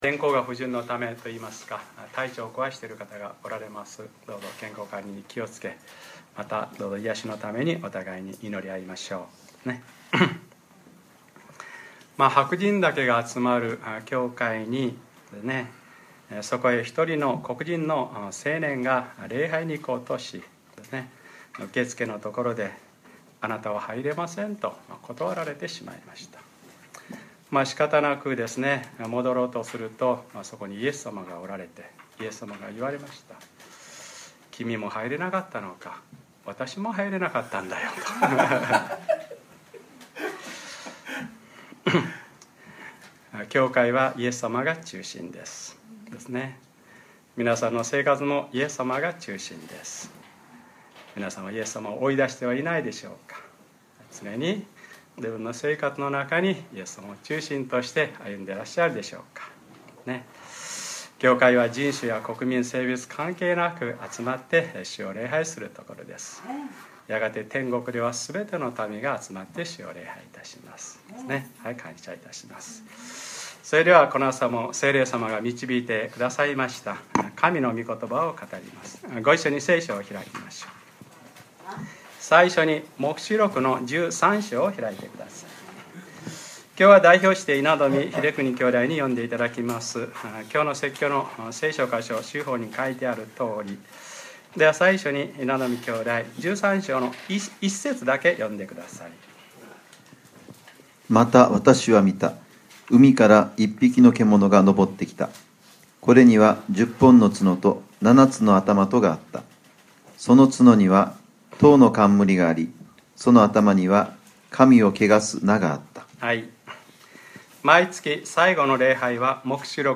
2013年6月30日(日）礼拝説教 『黙示録ｰ２０ たといそうでなくても』